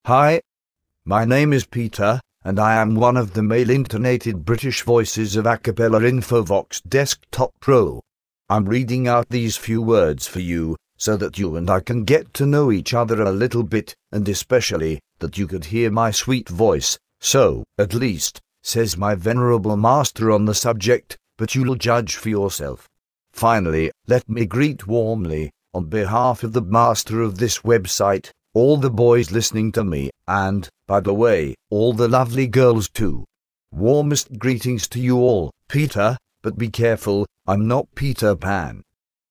Texte de démonstration lu par Peter, voix masculine anglaise d'Acapela Infovox Desktop Pro
Écouter la démonstration de Peter, voix masculine anglaise d'Acapela Infovox Desktop Pro